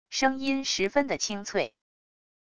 声音十分的清脆wav音频